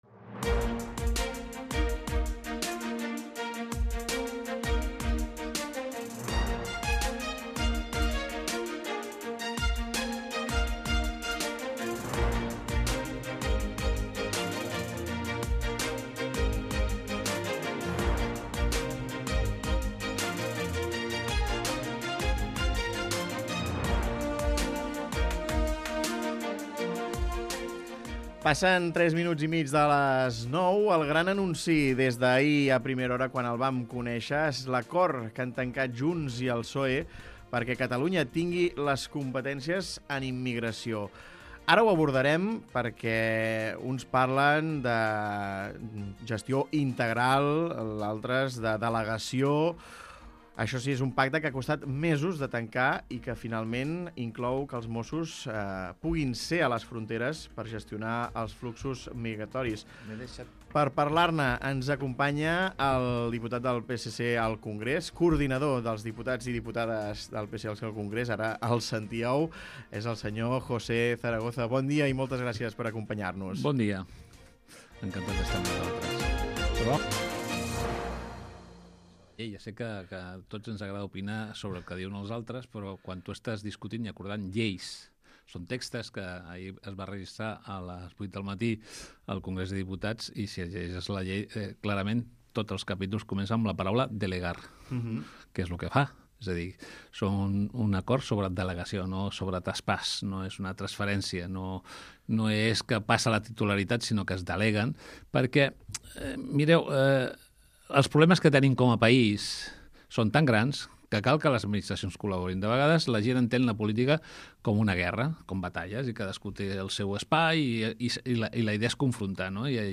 Escolta l'entrevista amb José Zaragoza, coordinador dels diputats i diputades del PSC al Congrés